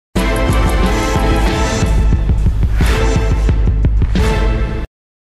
Звуки завершения, эффект
Звук Мелодия для конечной точки